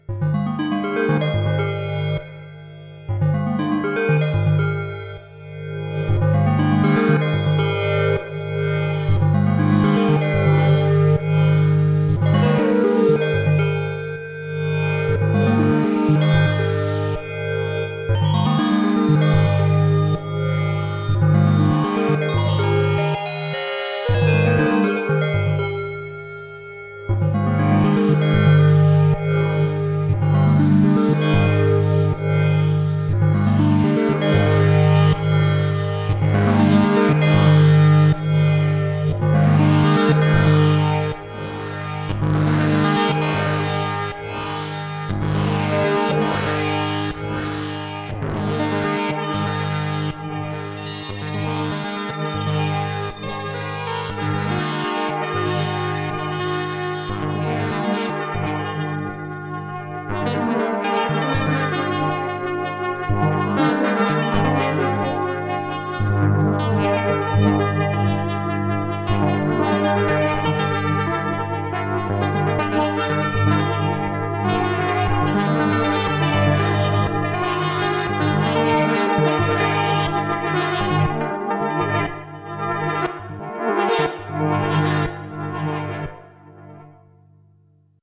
WARNING: NOT A PROPHET X SOUND.